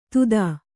♪ tutdā